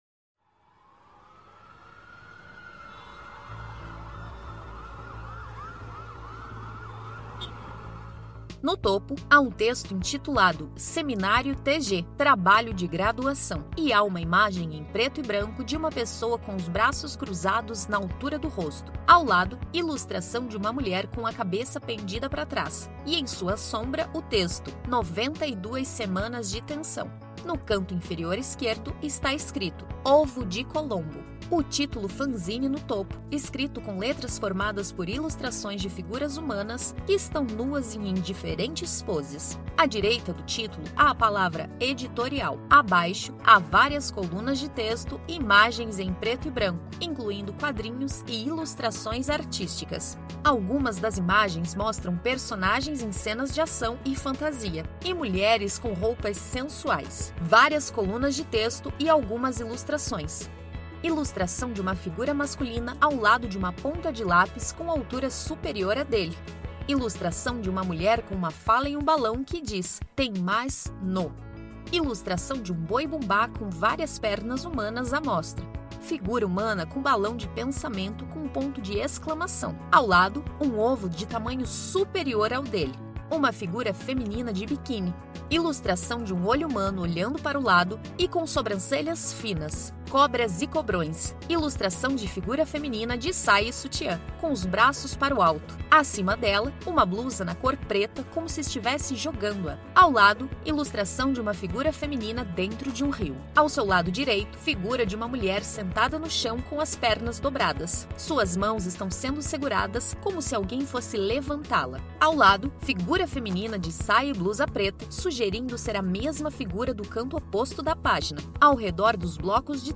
Audiodescrição do Fanzine n° 7